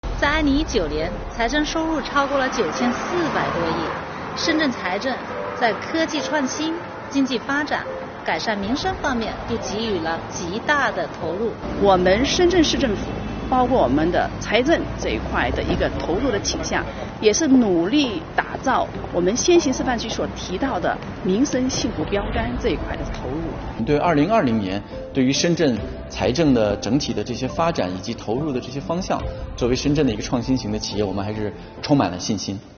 代表委员话财政！